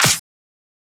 edm-clap-55.wav